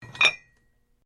Звук установки грифа на стойку для штанги